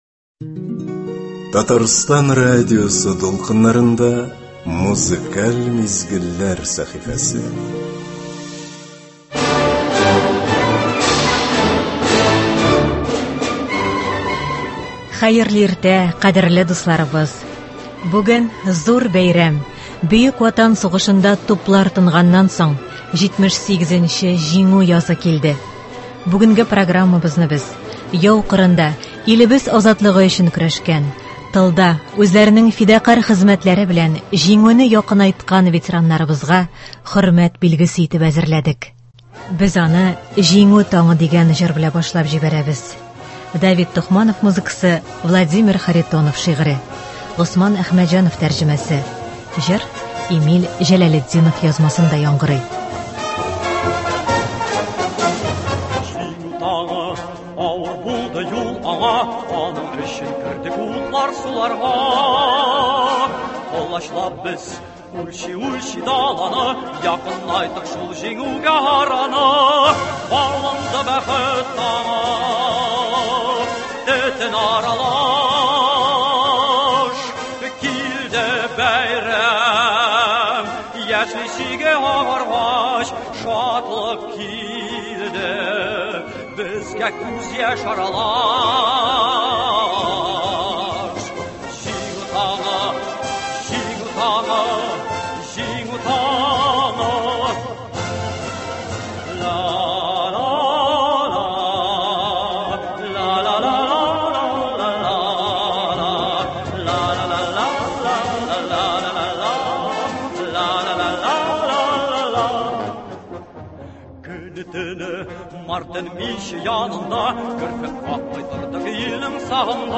Бәйрәм концерты.